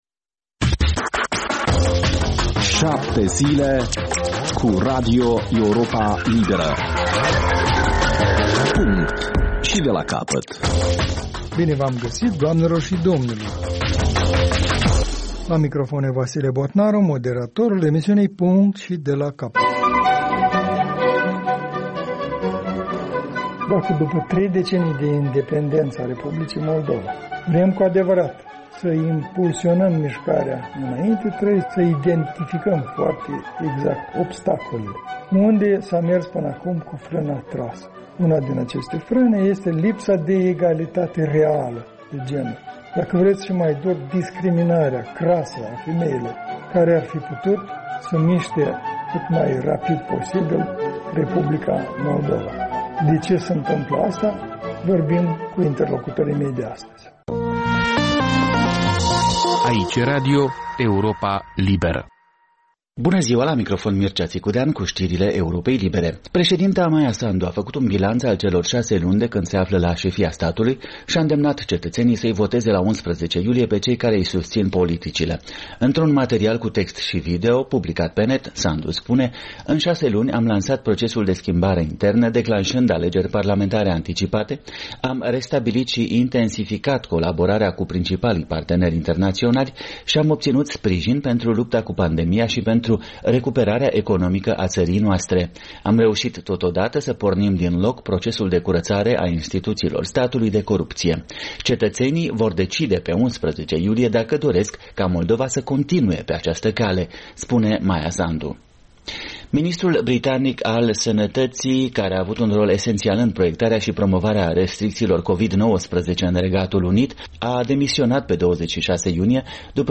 Emisiunea duminicală de dezbateri